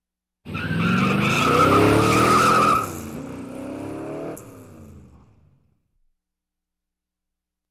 Звуки гоночных машин
Звук гоночного автомобиля с пробуксовкой